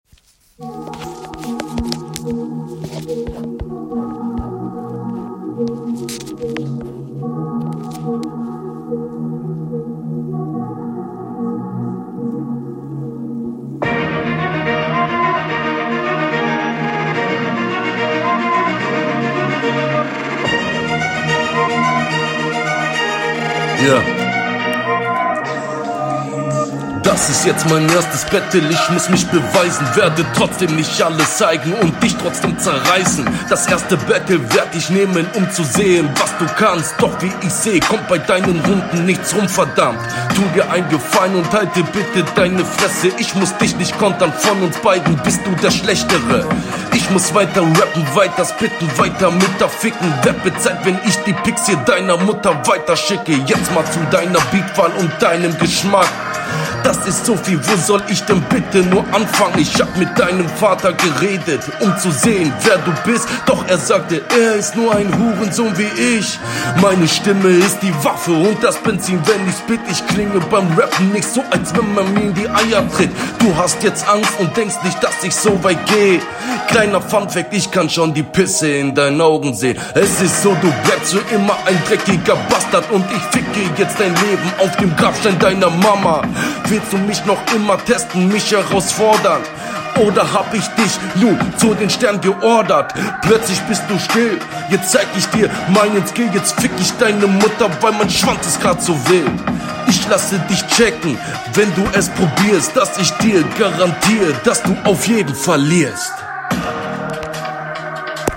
Beim Flow finde ich deinen Stimmeinsatz ein Stück besser als in der HR! Technik: gleich …